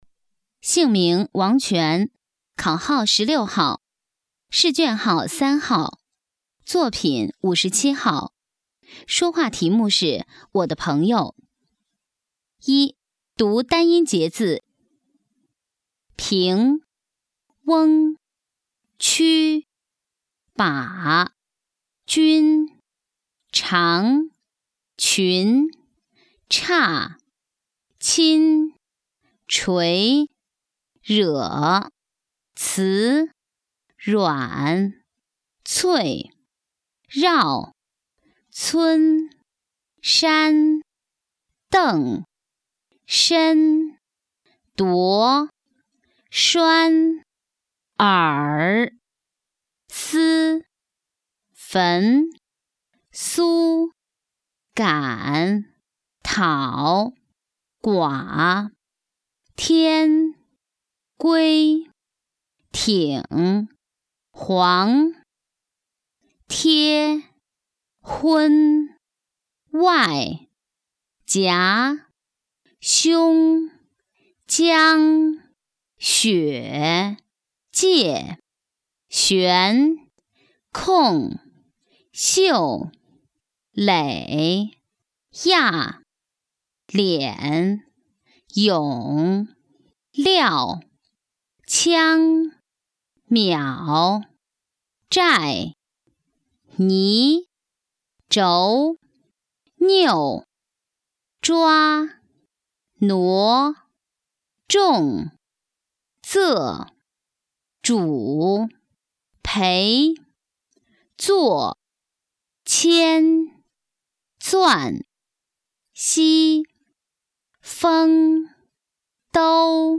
一甲语音面貌示范.mp3 - 景德镇人文学院